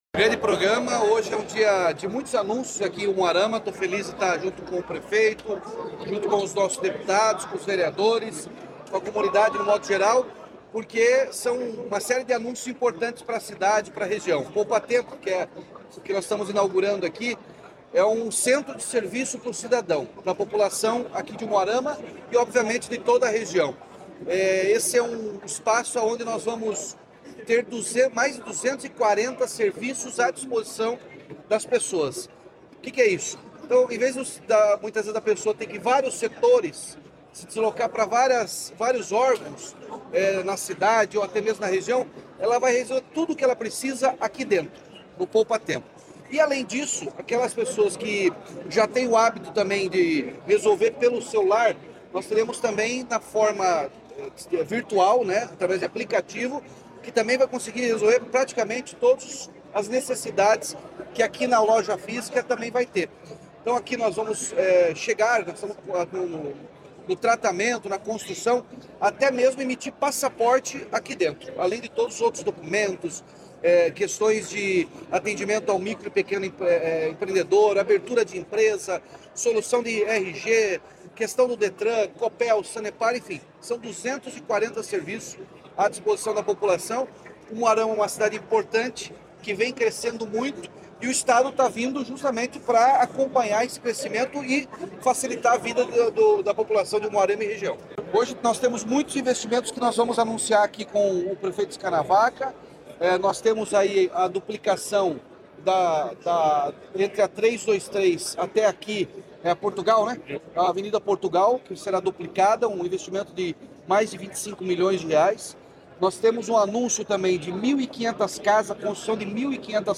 Sonora do governador Ratinho Junior sobre a inauguração do Poupatempo de Umuarama